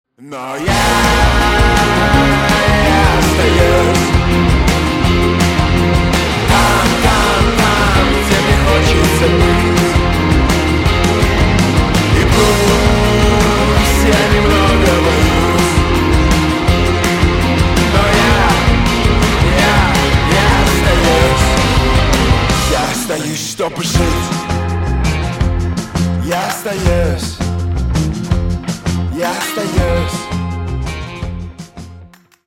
• Качество: 320 kbps, Stereo
Рок Металл